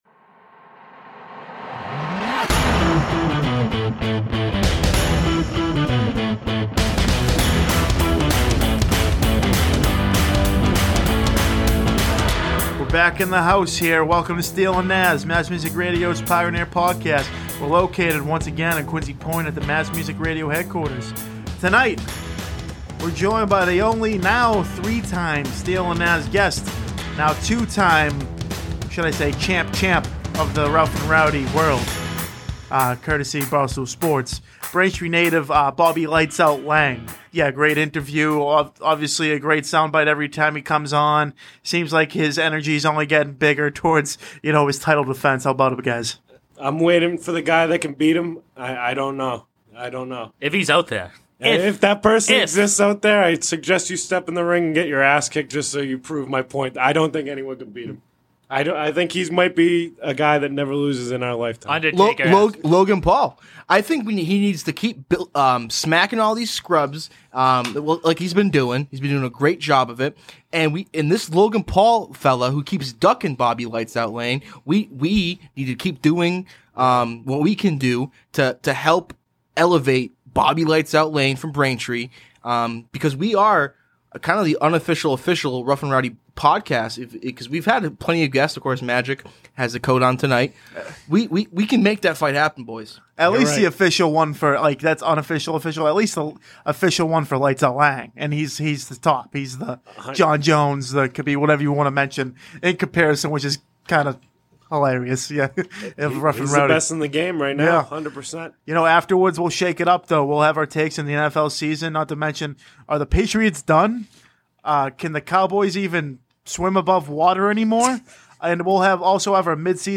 at the Mass Music Radio Station in Quincy, Massachusetts.